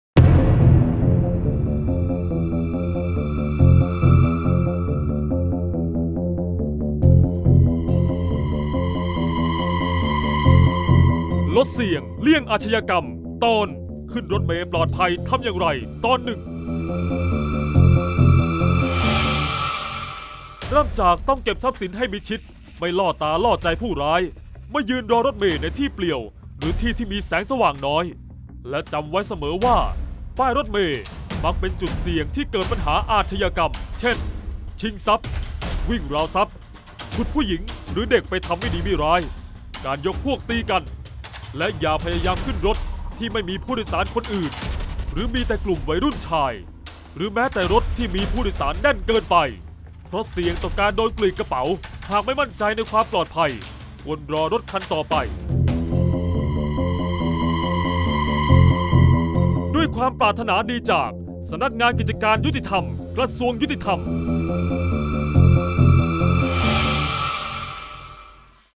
เสียงบรรยาย ลดเสี่ยงเลี่ยงอาชญากรรม 19-ขึ้นรถเมย์ปลอดภัย-1